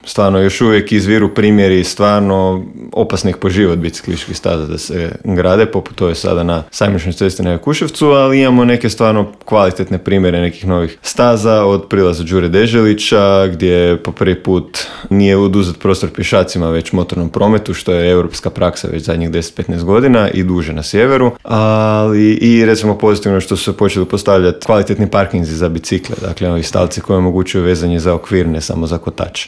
Intervju